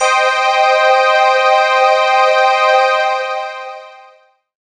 37l02pad1-c.wav